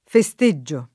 festeggio [ fe S t %JJ o ]